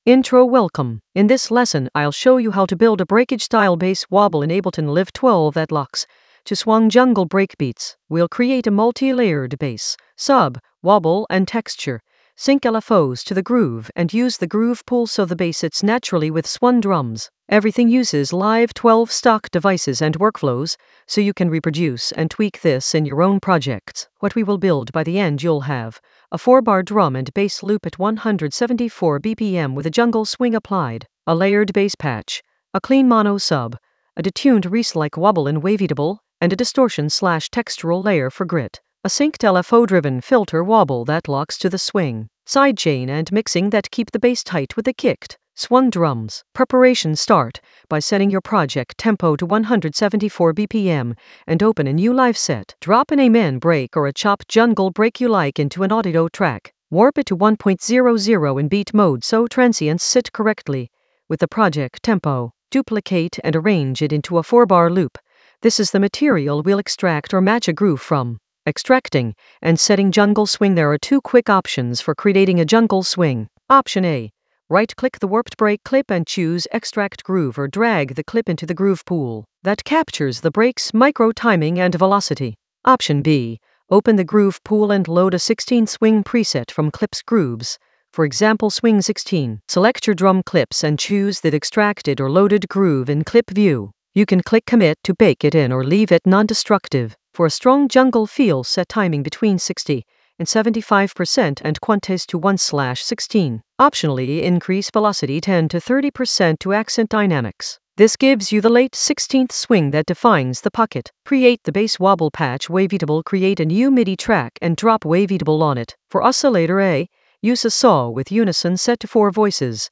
An AI-generated intermediate Ableton lesson focused on Breakage bass wobble in Ableton Live 12 with jungle swing in the Drums area of drum and bass production.
Narrated lesson audio
The voice track includes the tutorial plus extra teacher commentary.